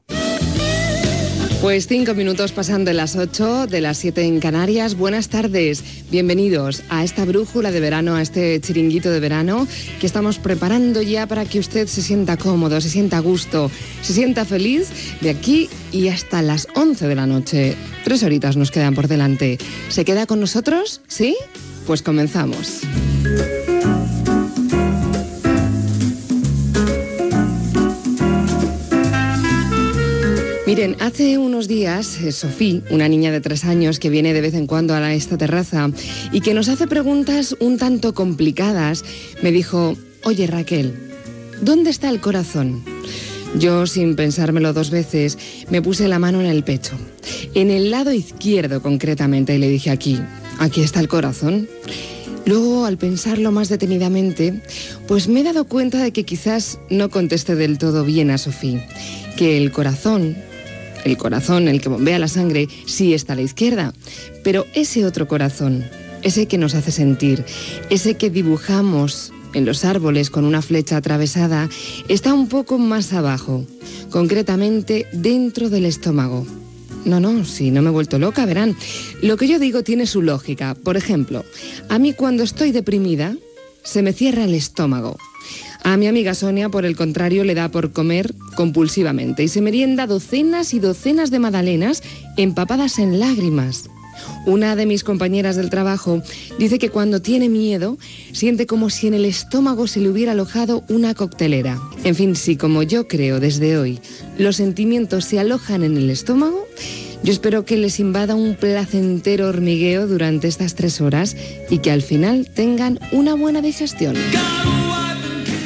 Monòleg d'inici del programa sobre el lloc on està el cor
Entreteniment
FM